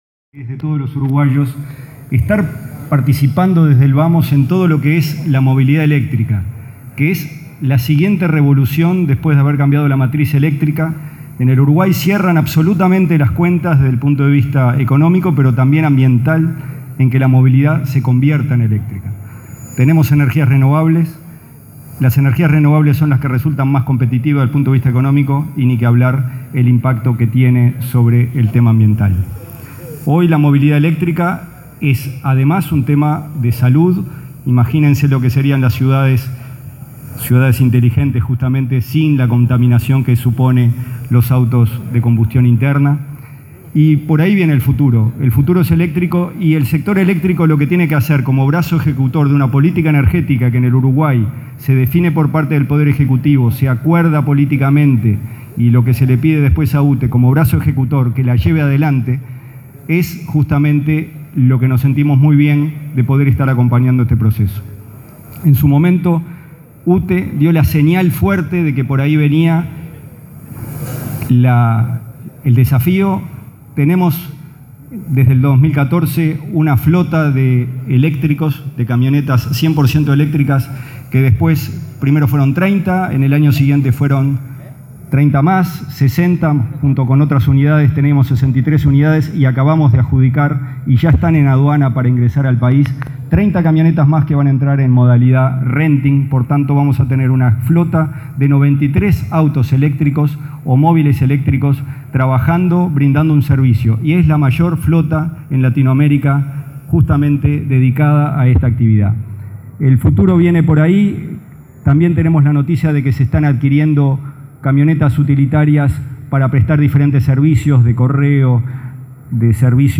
“Si toda la flota de movilidad de Uruguay fuera eléctrica, significaría solo un 20 % más de la demanda que UTE podría suministrar”, afirmó su presidente, Gonzalo Casaravilla, en el lanzamiento del “Salón de la movilidad eléctrica y ciudades inteligentes”. Adelantó que al finalizar el año el país tendrá un punto de recarga en todas las ciudades capitales y UTE dispondrá de 93 unidades eléctricas.